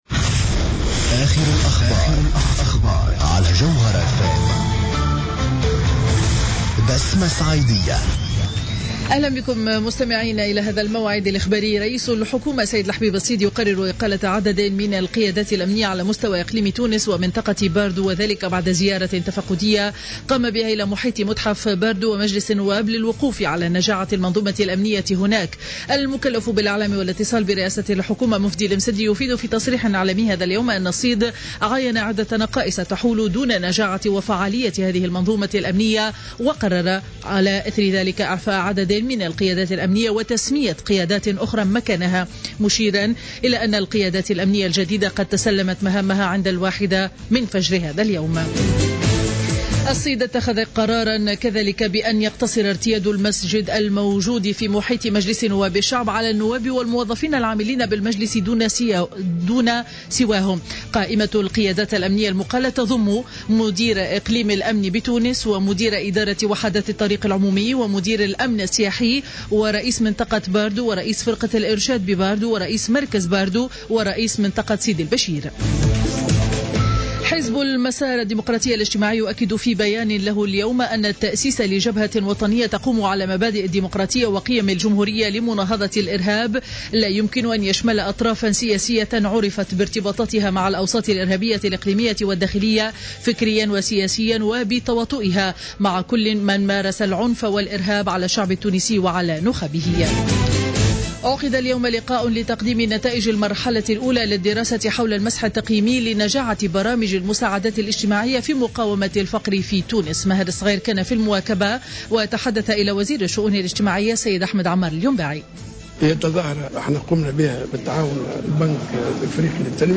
نشرة أخبار منتصف النهار ليوم الاثنين 23 مارس 2015